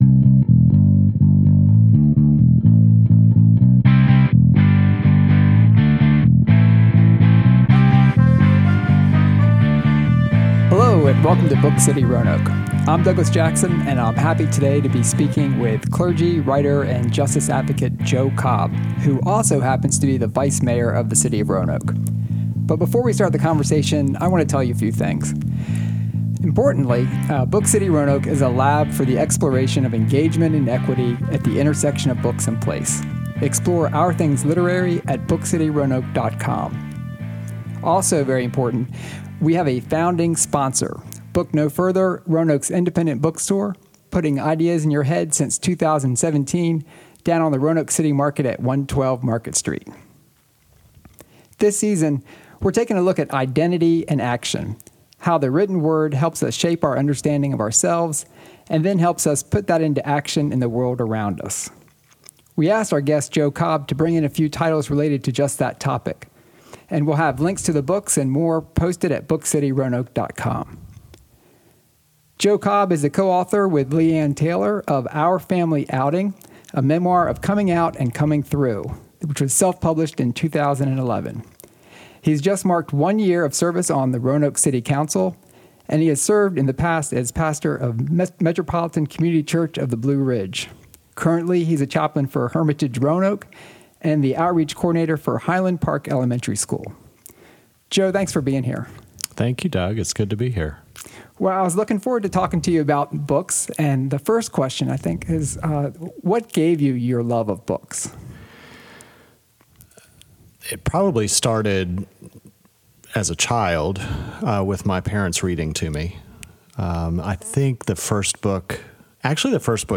Identity and Action: We continue our exploration of how the written word shapes our sense of self and how we put that into work in the world around us with a conversation with City of Roanoke Vice Mayor Joe Cobb.